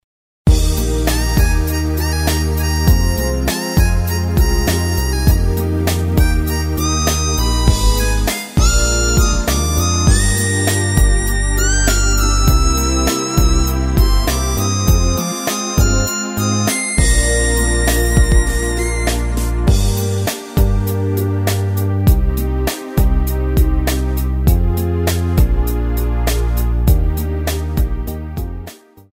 엔딩이 페이드 아웃이라 엔딩을 만들어 놓았습니다.
Eb
앞부분30초, 뒷부분30초씩 편집해서 올려 드리고 있습니다.
중간에 음이 끈어지고 다시 나오는 이유는